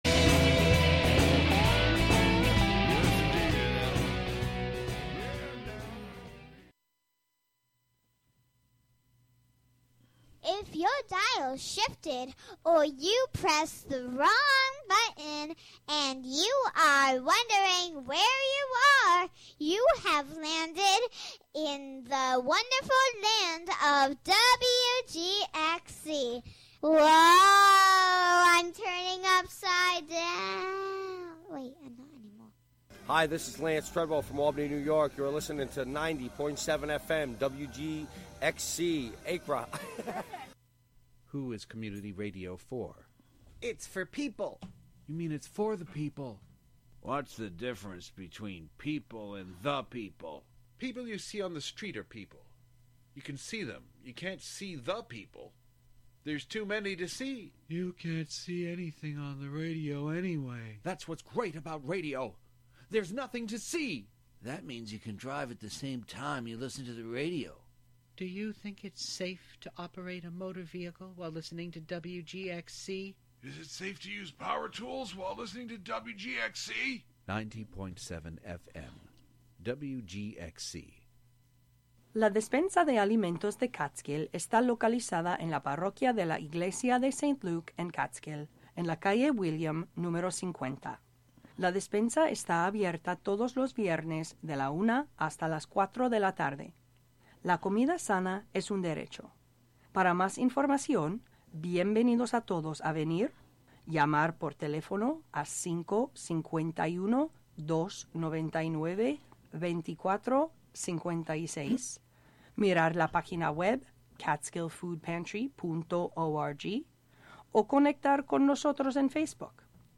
An upbeat music show featuring the American songbook as interpreted by contemporary artists as well as the jazz greats in a diverse range of genres live from WGXC's Catskill studio.